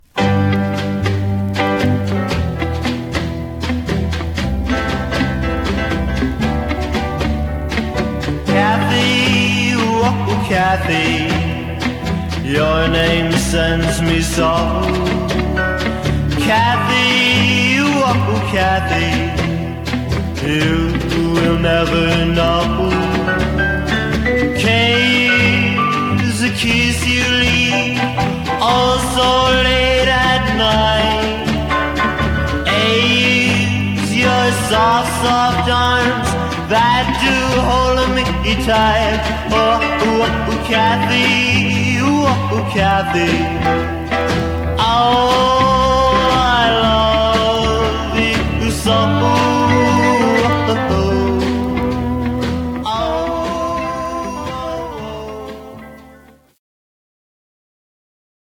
Stereo/mono Mono
Teen (ie. Annette, Paul Anka)